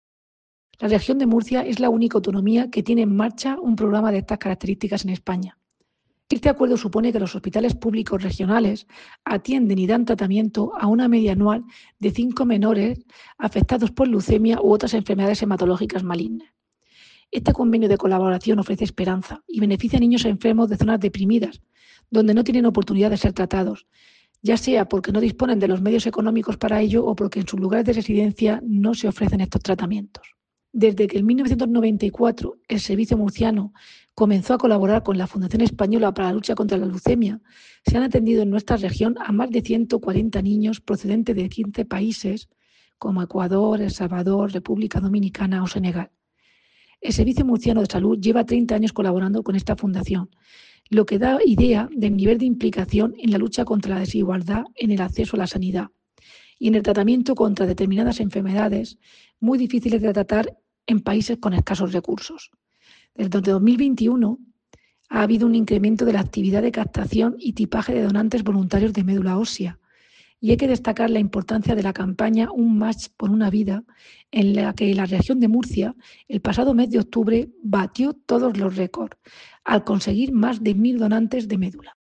Declaraciones de la directora general de Atención Hospitalaria, Irene Marín, sobre el convenio del SMS con la Fundación de Lucha contra la Leucemia.